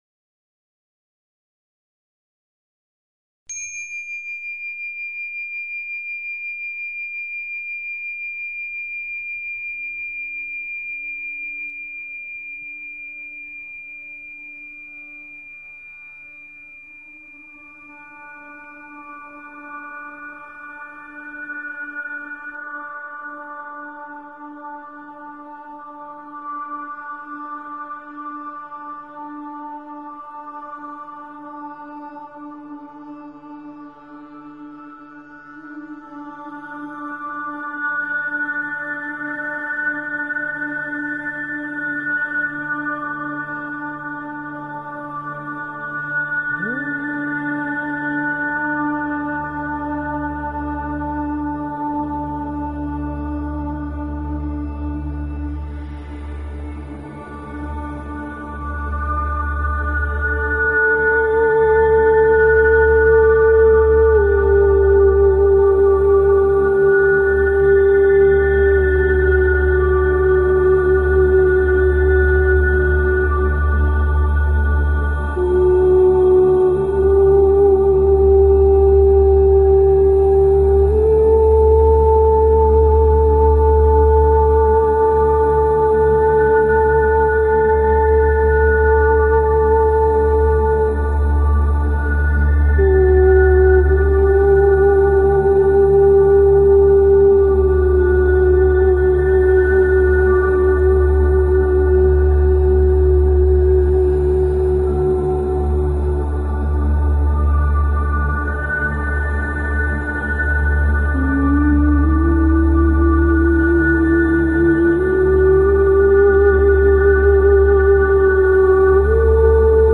Talk Show Episode, Audio Podcast, Radiance_by_Design and Courtesy of BBS Radio on , show guests , about , categorized as
The show offers you a much needed spiritual tune up – gives you the means to hold your own as you engage the crazy dynamics that occupy our ever changing planet. Radiance By Design is specifically tailored to the energies of each week and your calls dictate our on air discussions.